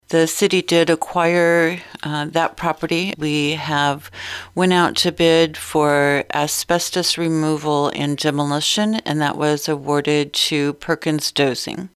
A contract for demolition of a house at 821 Walnut St. owned by the City of Chillicothe was approved by the City Council. City Administrator Rose Frampton explains they accepted the lowest responsible bid.